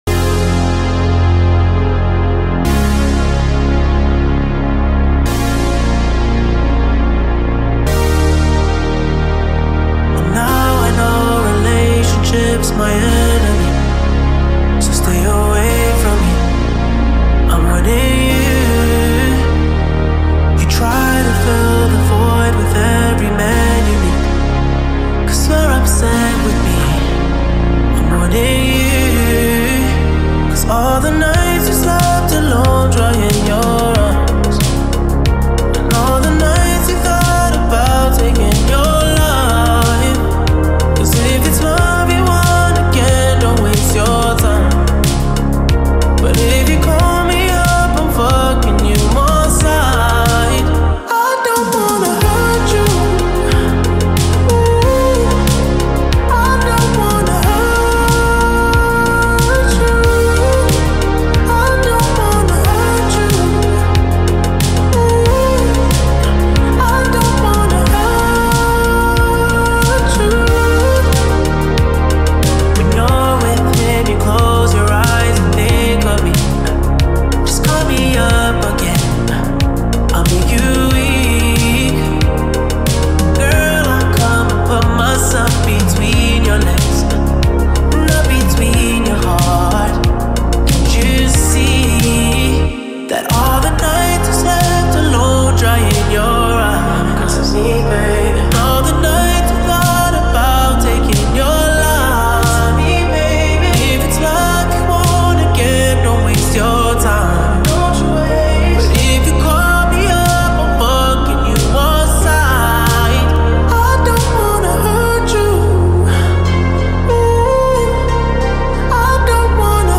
این آهنگ شبیه آهنگ های 1980 ریمیکس شده (: